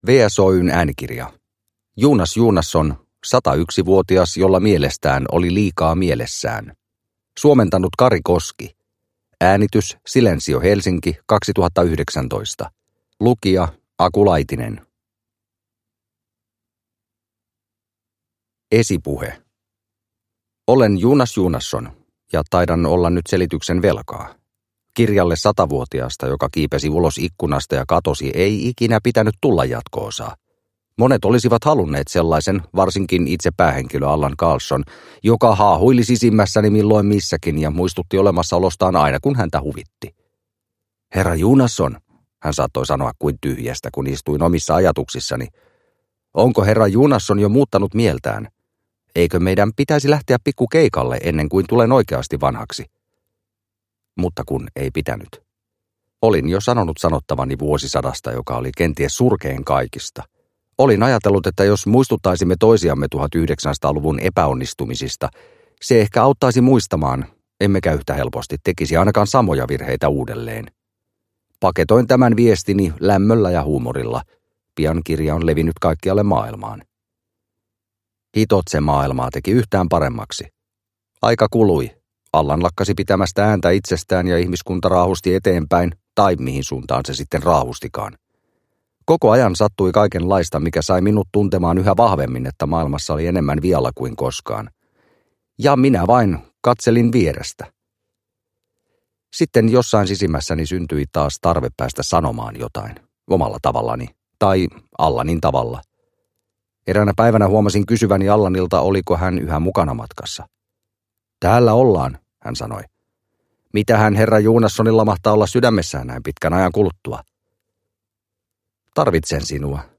Satayksivuotias jolla mielestään oli liikaa mielessään – Ljudbok